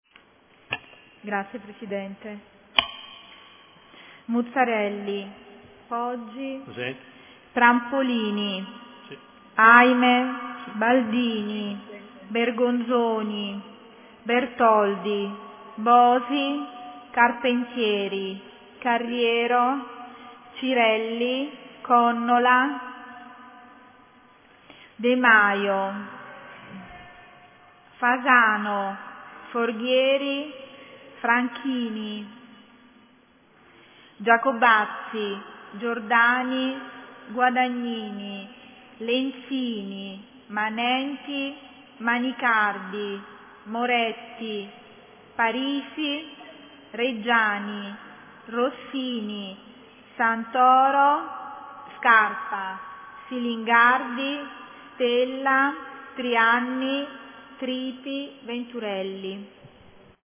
APPELLO
Audio Consiglio Comunale